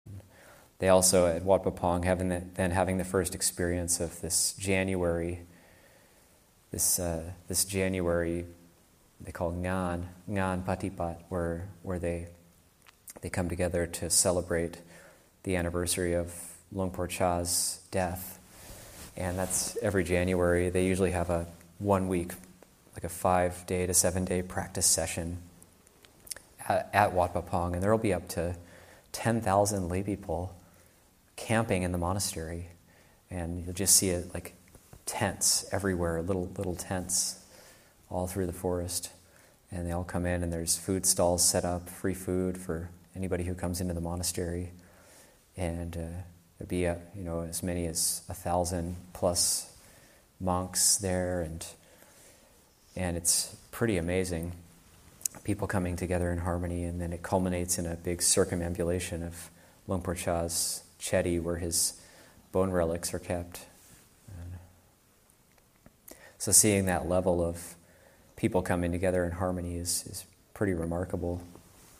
Recollection: The annual January gathering at Wat Pah Pong.